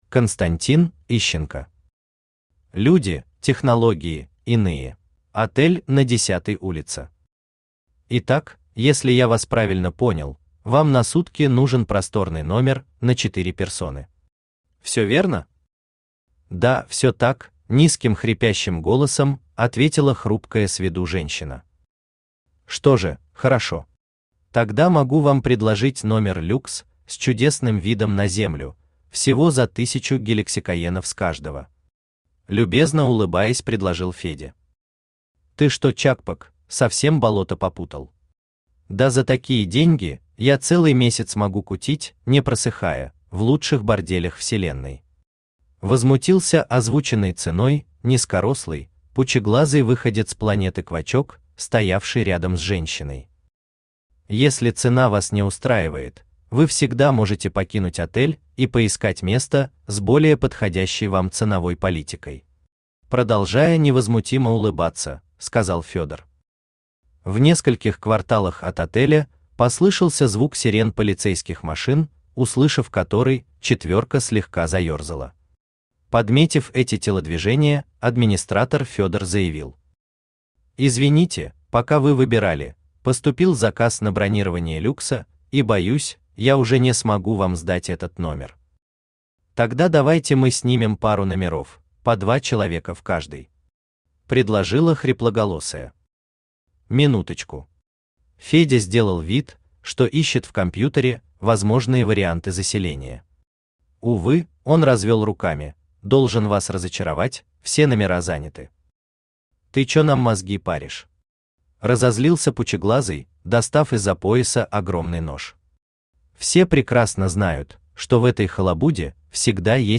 Аудиокнига Люди, Технологии, Иные | Библиотека аудиокниг
Aудиокнига Люди, Технологии, Иные Автор Константин Евгеньевич Ищенко Читает аудиокнигу Авточтец ЛитРес.